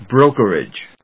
音節bro・ker・age 発音記号・読み方
/bróʊk(ə)rɪdʒ(米国英語), brˈəʊk(ə)rɪdʒ(英国英語)/